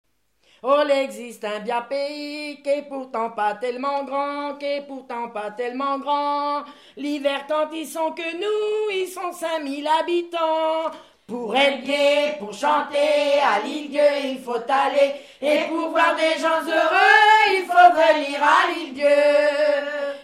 Île-d'Yeu (L')
chansons traditionnelles
Pièce musicale inédite